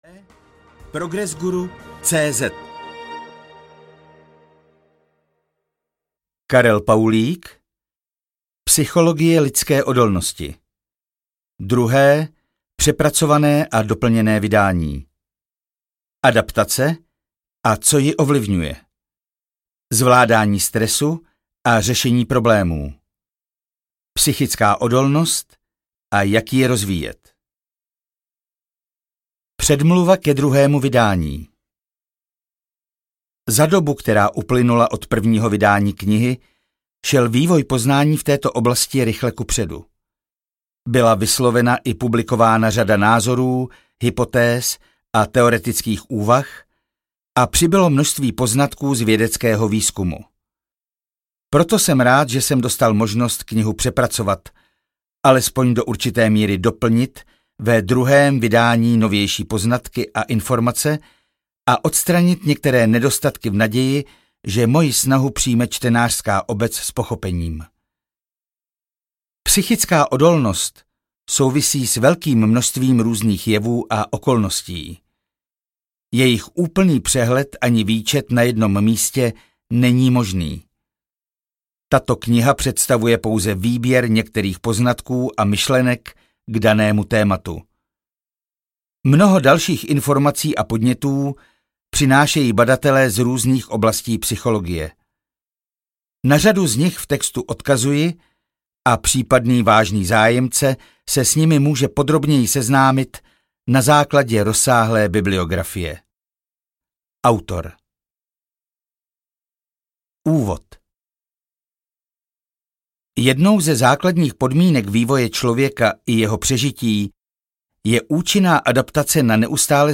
Psychologie lidské odolnosti audiokniha
Ukázka z knihy